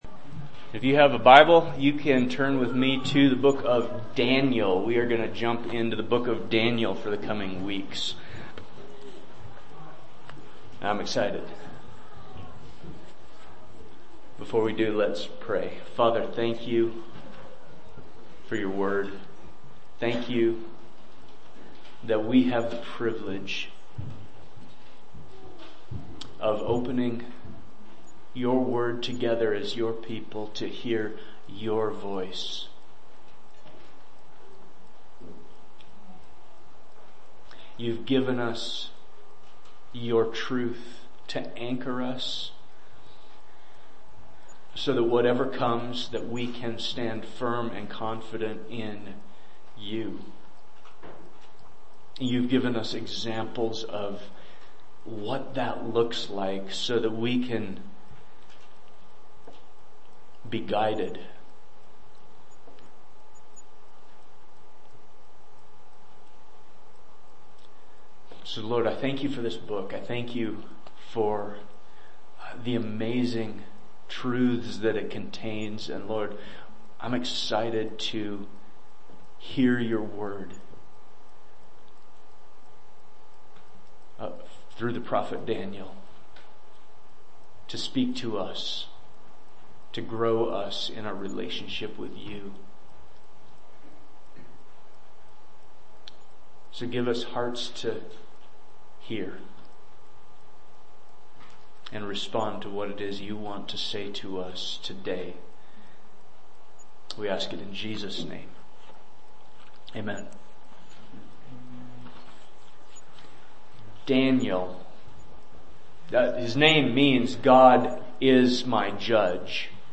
Sermons | Ephraim Church of the Bible